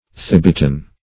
Search Result for " thibetan" : The Collaborative International Dictionary of English v.0.48: Thibetan \Thib"e*tan\, a. Of or pertaining to Thibet.